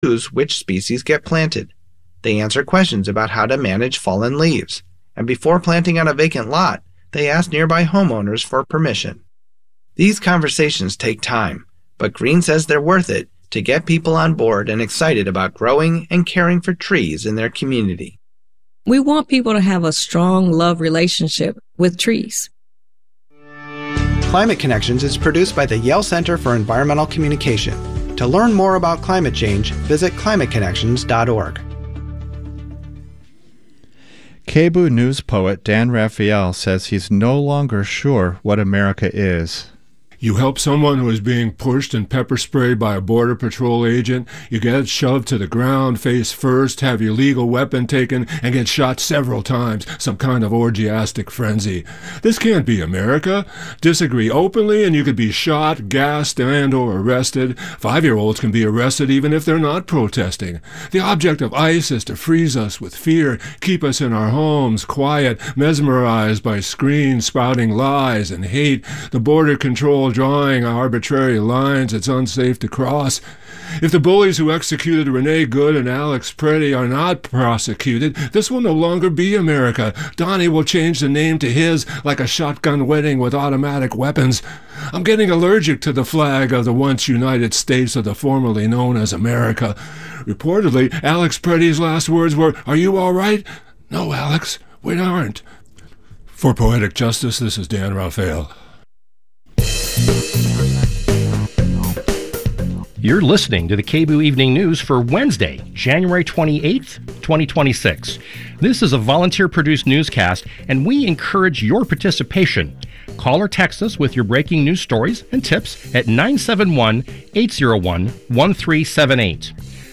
News In Depth: Interview with Oregon Senate President Rob Wagner